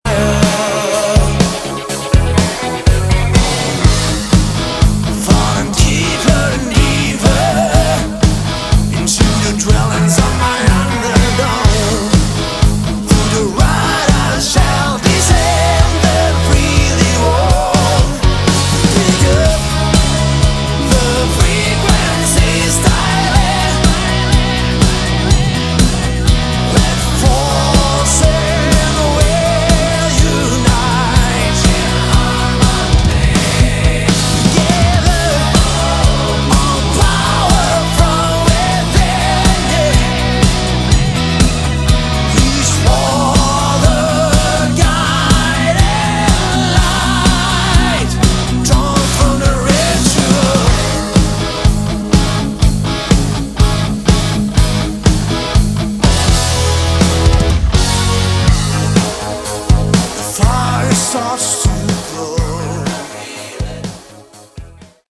Category: Melodic Rock
guitars, keyboards
lead vocals, guitars
drums
bass
saxophone
backing vocals